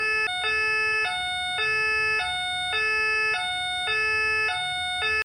Sirène de police Française
Tonalité Gendarmerie
2. Tonalité n°9 – Gendarmerie Française :
– Signal sonore réglementaire pour les interventions de la gendarmerie française
tonalite-9-sirene-gendarmerie-francaise.mp3